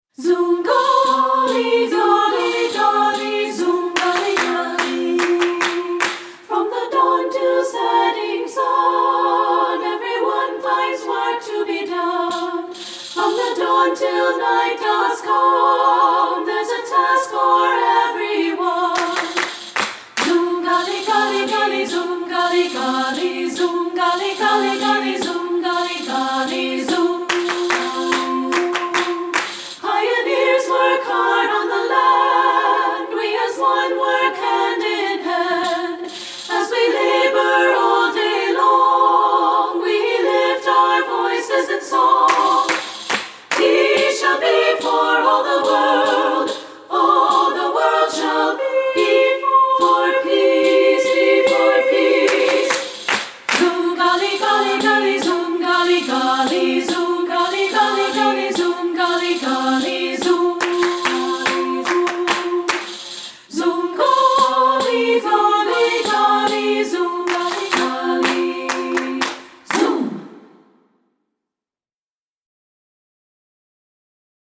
Composer: Palestinian Work Sng
Voicing: SSA and Piano